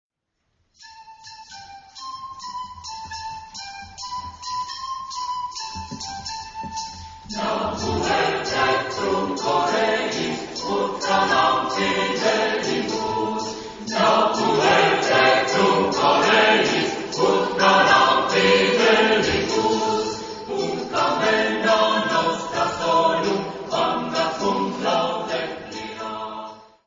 Género/Estilo/Forma: Sagrado ; Canción de Navidad
Carácter de la pieza : alegre
Tipo de formación coral: SATB  (4 voces Coro mixto )
Tonalidad : la eólico